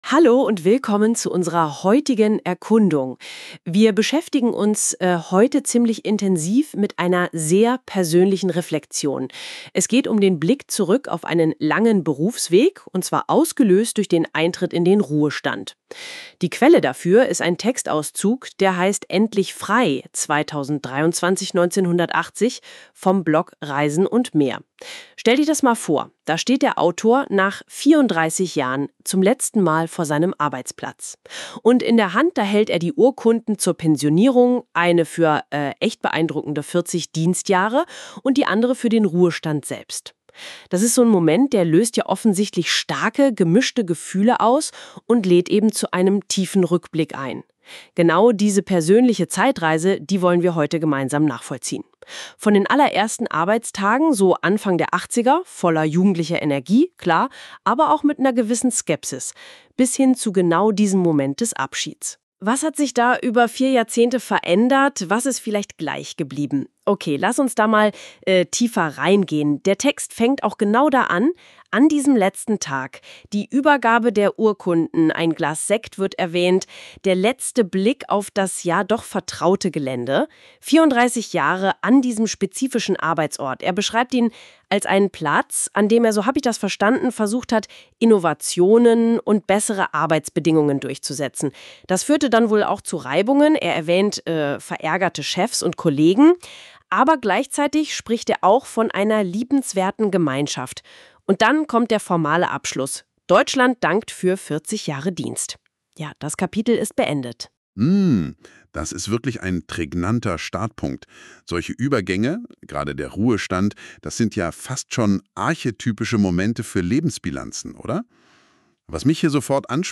Hier hatte ich die Arbeitstage der letzten 34 Jahre größtenteils verbracht und versucht, Innovationen und bessere Arbeitsbedingungen unter einen Hut zu bringen, Chefs und Kollegen verärgert und eine liebenswerte Gemeinschaft erlebt. Meine kleine Ansprache zum Anlass meiner Verabschiedung im Juli 2023.